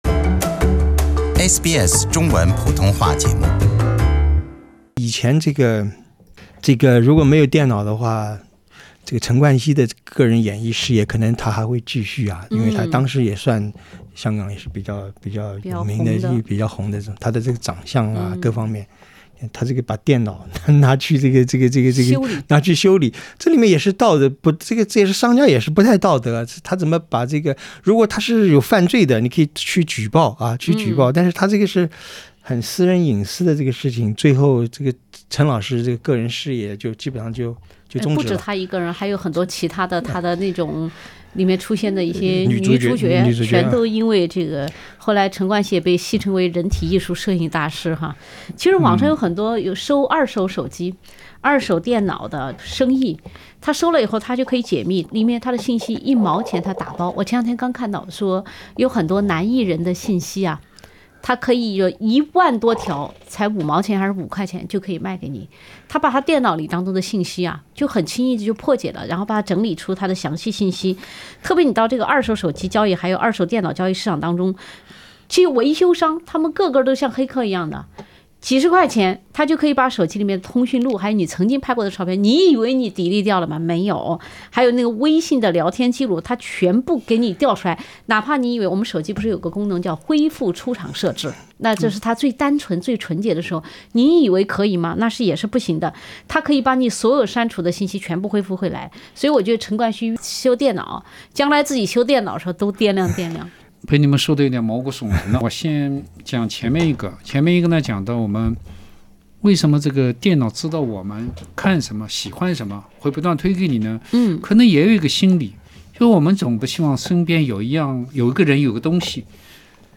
欢迎收听SBS 文化时评栏目《文化苦丁茶》，大数据的危机（第2集）：大数据里我们都裸着吗？请点击收听。